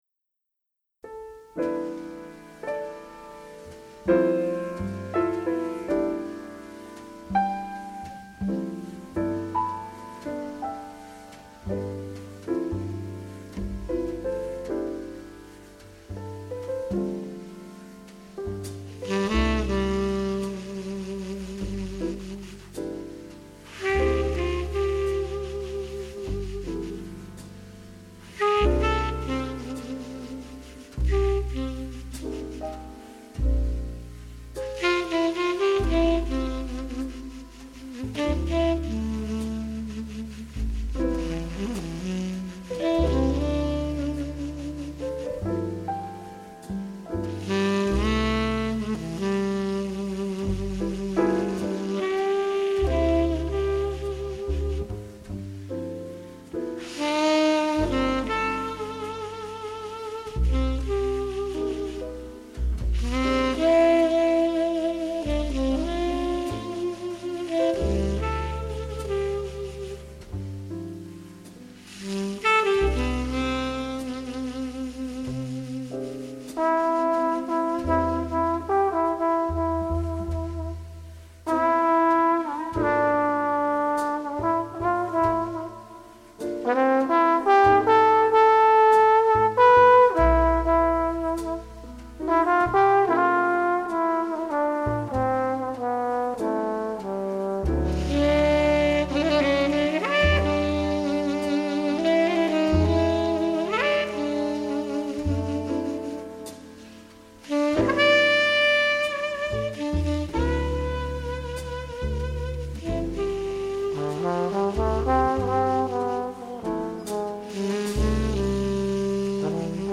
★ 八位爵士天后與六位爵士天王傳世名曲！